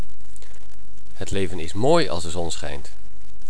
accent op mooi
leven-mooi.wav